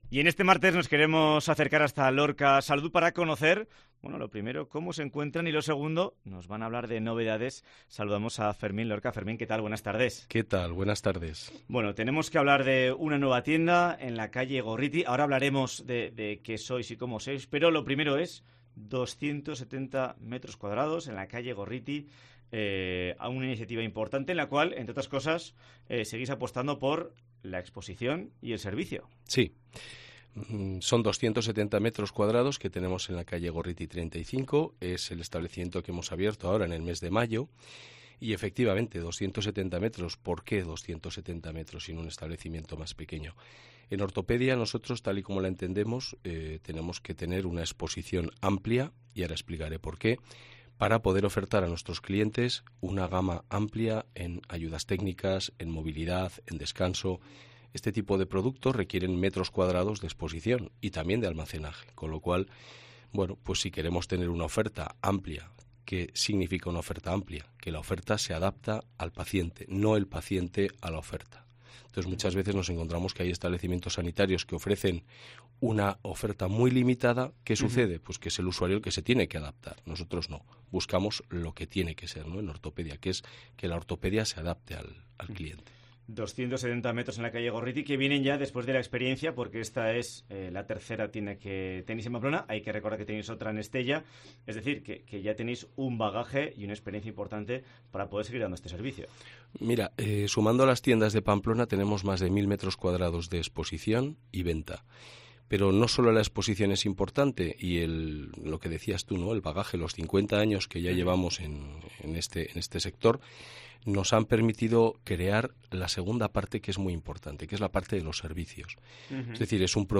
Madrid - Publicado el 21 jun 2022, 12:27 - Actualizado 18 mar 2023, 16:48